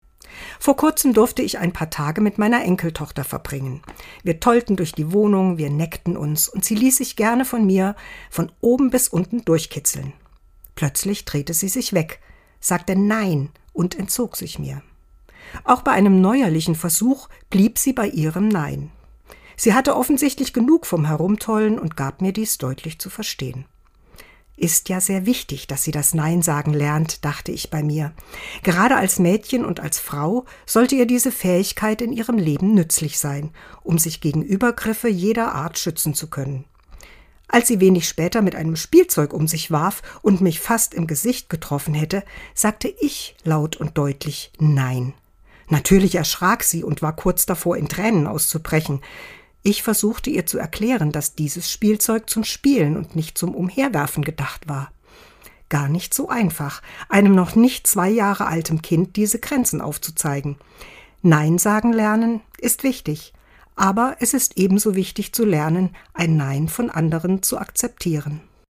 Dezember 2024, Autorin und Sprecherin ist